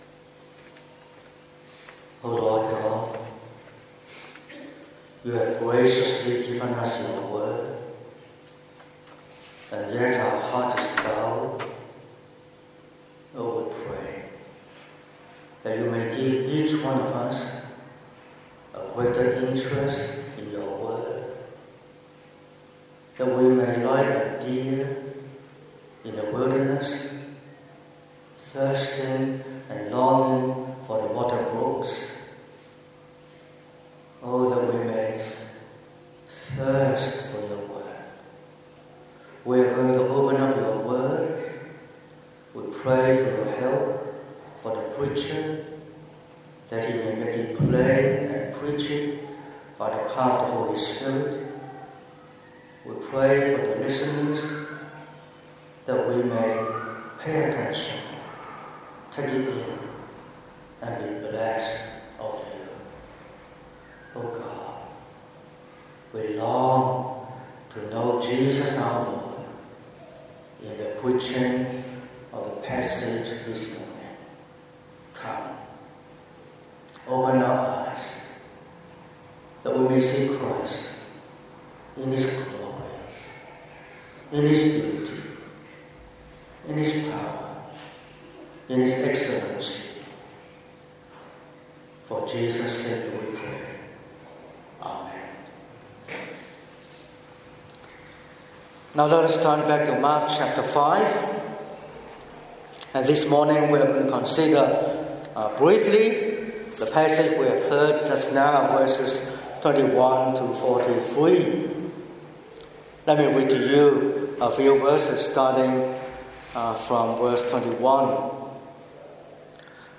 07/09/2025 – Morning Service: In the face of illness and death
Sermon Outline